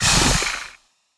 Index of /App/sound/monster/misterious_diseased_bow